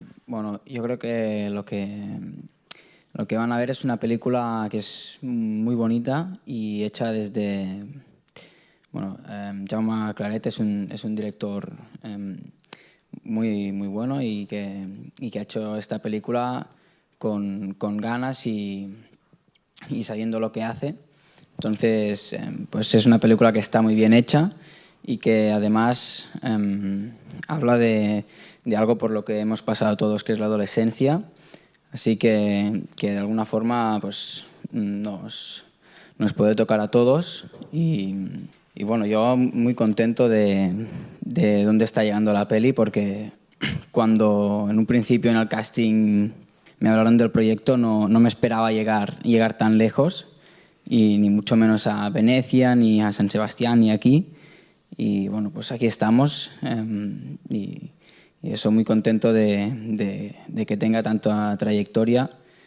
20-11_fical_extrano_rio_actor.mp3